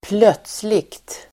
Uttal: [²pl'öt:sli(k)t]